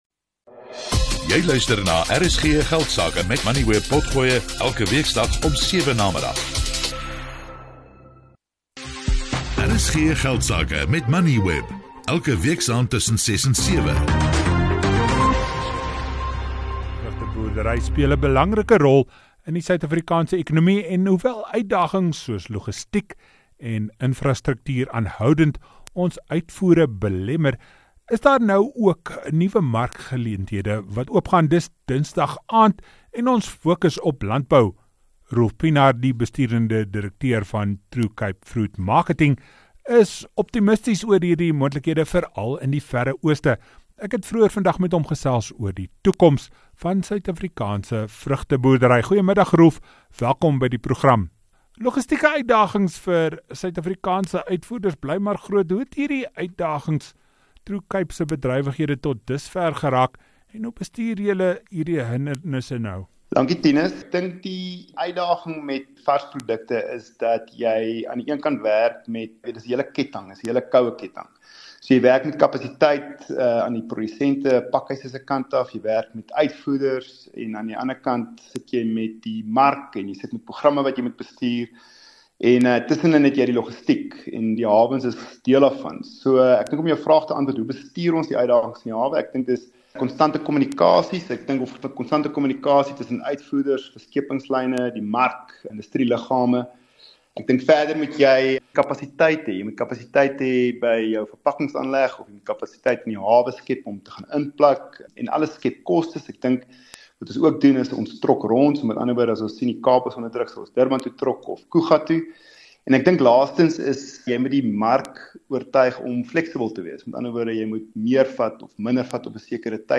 Kenners Antwoord luisteraarsvrae. Korttermynversekering kan nie dubbel wees nie.
Dit fokus op belangrike sakenuus, menings en beleggingsinsigte. RSG Geldsake word elke weeksdag tussen 18:10 en 19:00 op RSG (101 – 104 FM) uitgesaai.